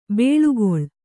♪ bēḷugoḷ